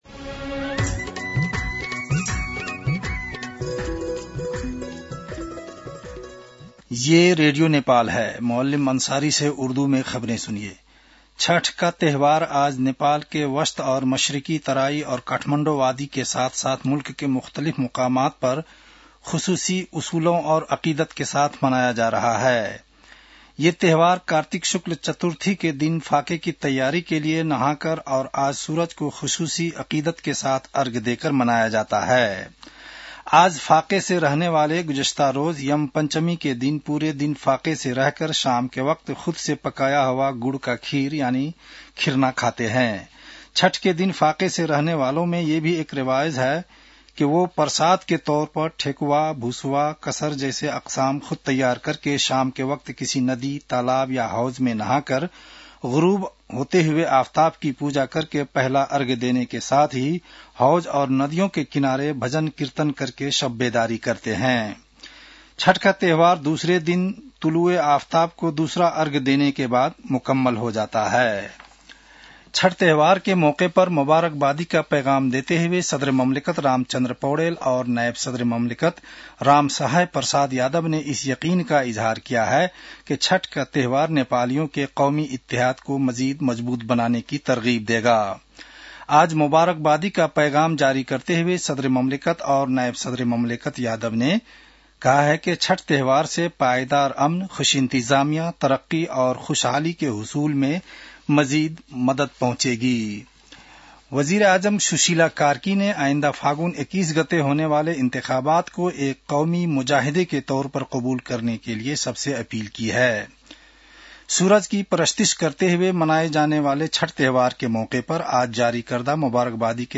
उर्दु भाषामा समाचार : १० कार्तिक , २०८२
Urdu-news-7-10-1.mp3